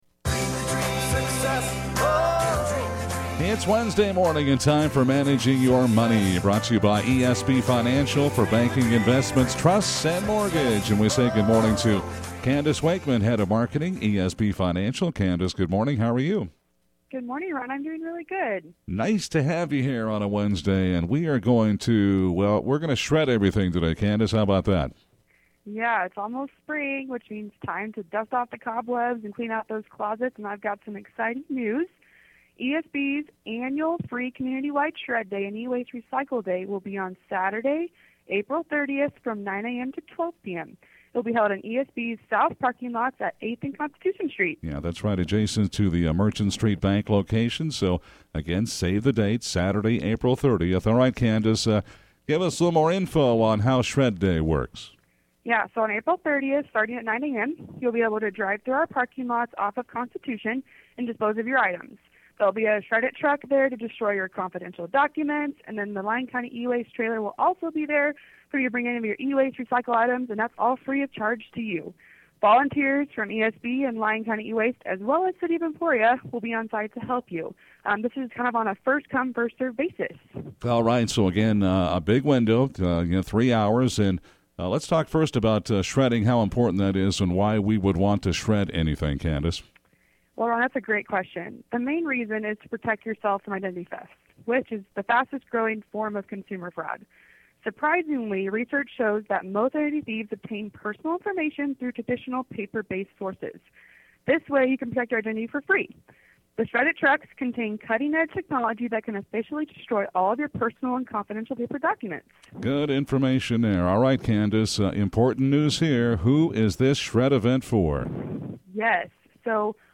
Live Call-In: Managing Your Money w/ ESB Financial